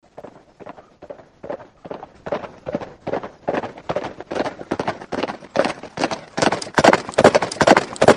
B_SABOTS_02.mp3